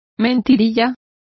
Complete with pronunciation of the translation of fibs.